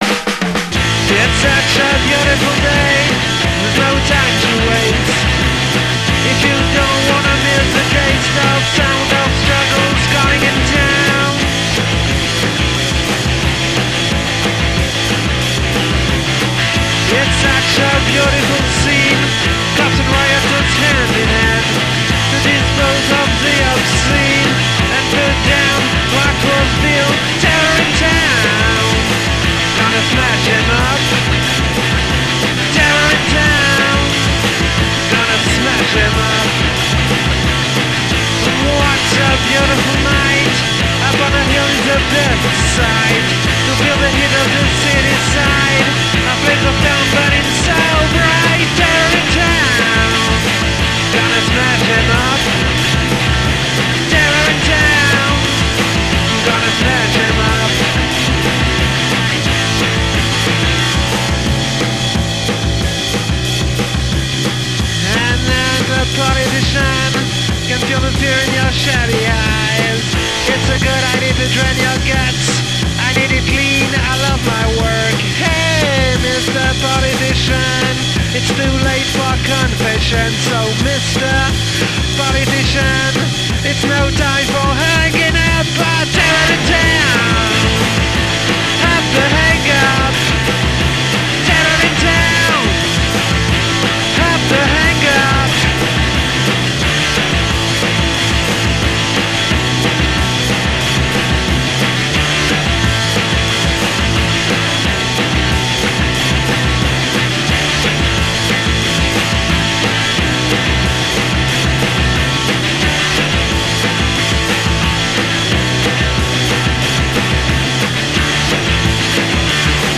Démo